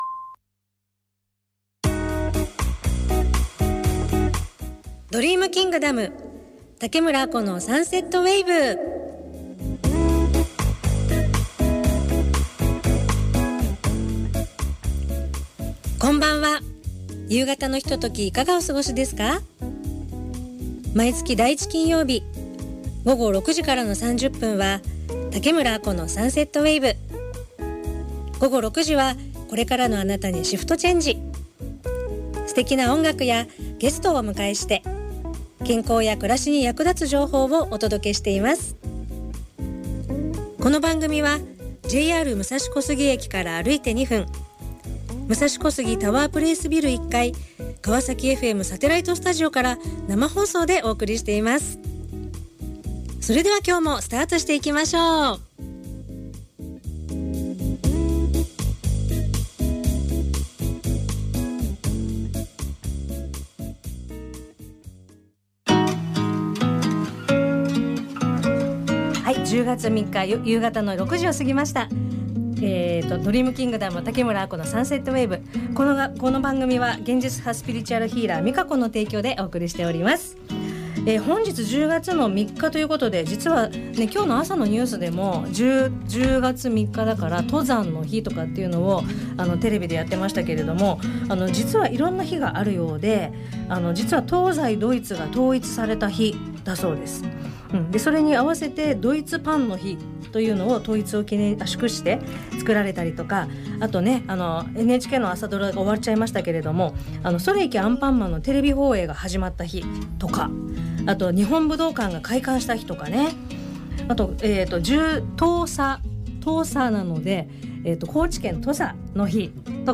＊かわさきFMサテライトスタジオから生放送